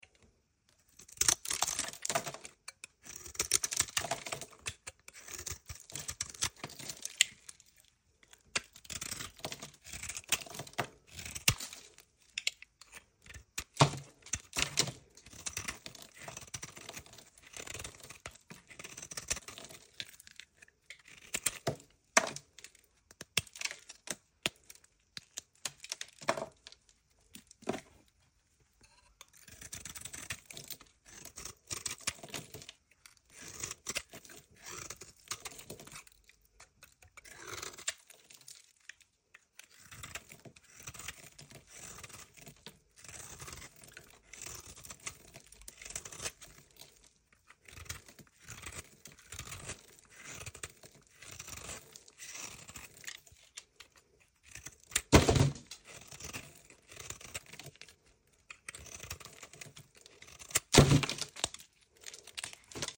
Cutting Dry Soap , Vintage Sound Effects Free Download